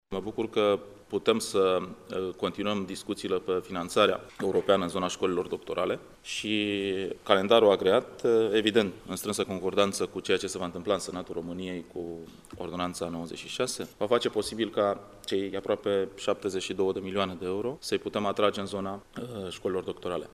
Prezent, la Iaşi, la Consiliului Naţional al Rectorilor, ministrul Educaţiei, Liviu Pop a precizat că în ceea ce priveşte finanţarea europeană pentru şcolile doctorale, România poate atrage 72 de milioane de euro, banii ce vor fi alocaţi pe criterii transparente: